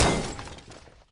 icePickaxe.opus